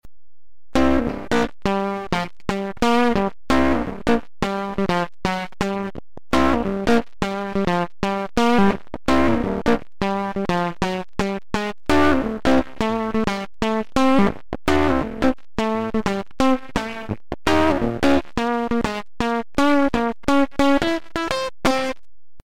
The following are mp3 samples that showcase different settings of Danstortion.
Guitar
Guitar_neg_100.mp3